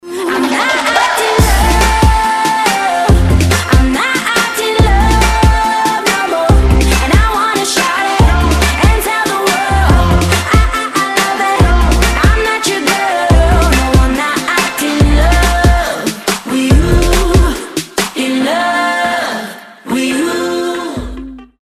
• Качество: 256, Stereo
поп
dance
RnB
vocal